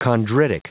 Prononciation du mot chondritic en anglais (fichier audio)